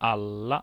File:Retroflex lateral approximant.ogg
Pronunciation of a retroflex lateral approximant, [ɭ] between two [a]s.
[aɭa]